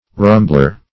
Rumbler \Rum"bler\, n.